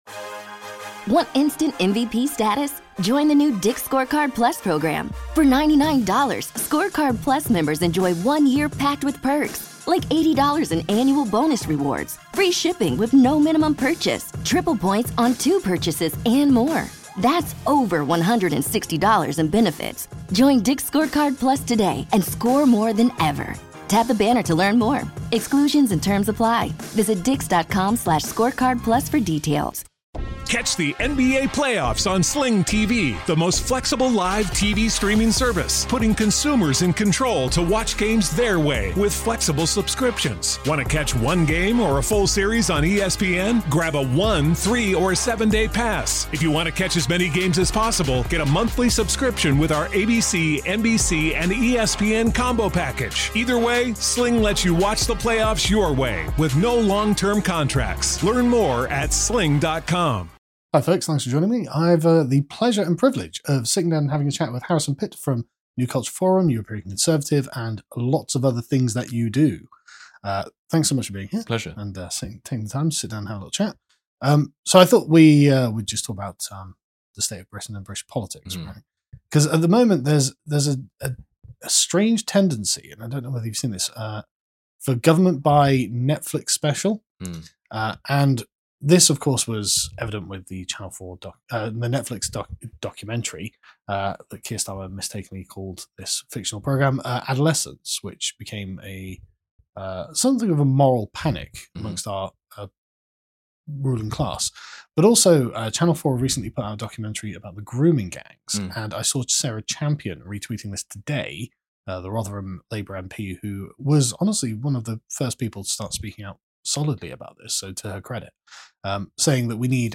PREVIEW: The Future of Britain | Interview